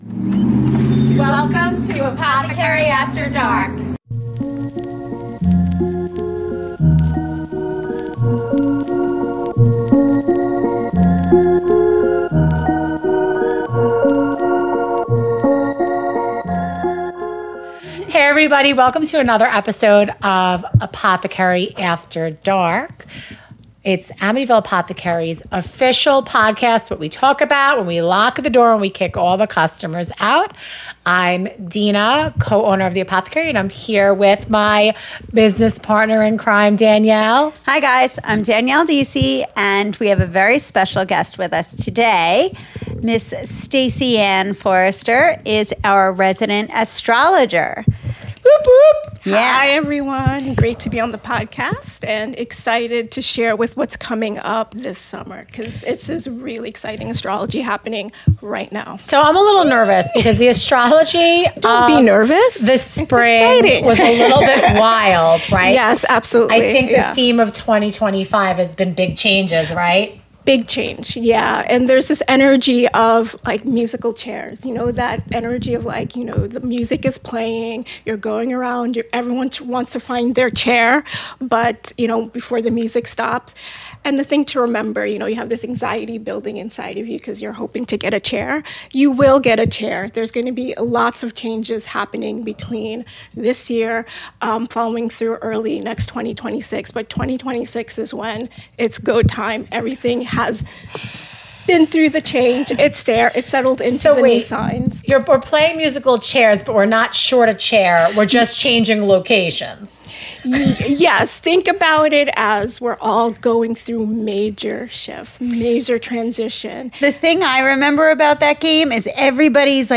Pour yourself a cozy cup and join us for a soul-nourishing conversation with herbalist